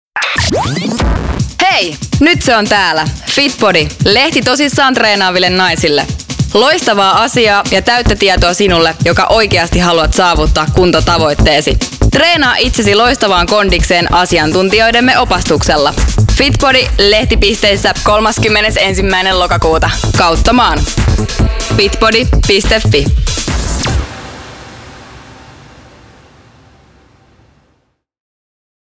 FitBODY-lehti Party FM-radiomainos